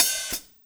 Cymbol Shard 12.wav